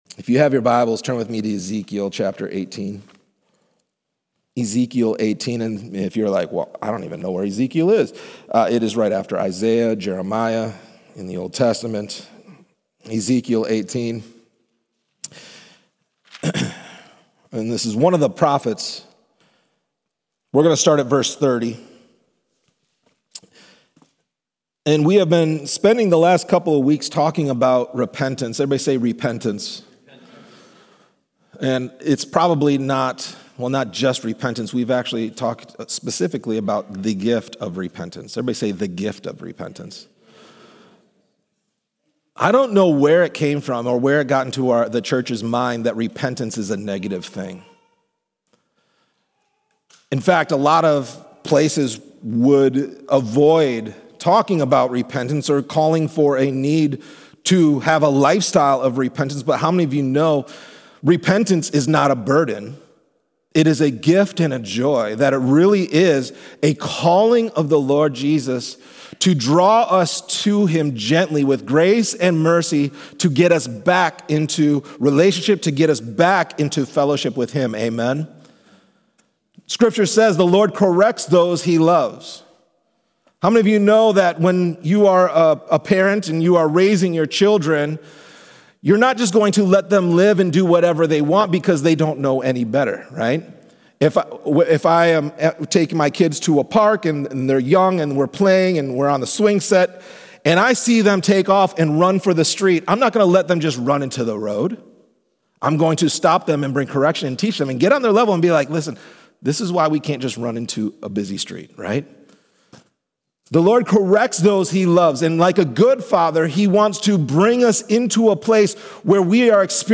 This sermon answers: 1.